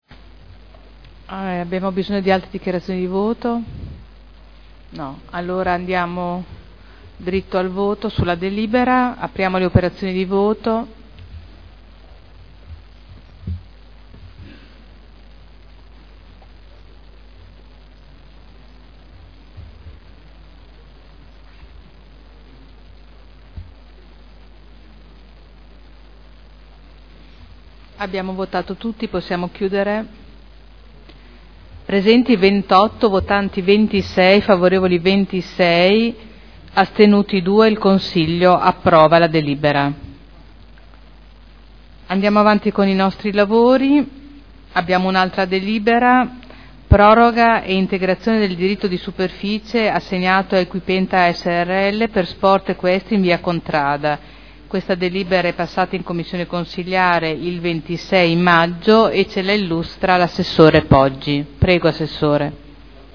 Seduta del 30/05/2011. Mette ai voti proposta di deliberazione: Variante al POC-RUE – Area in via Emilia Ovest – Z.E. 1481-1502 – Approvazione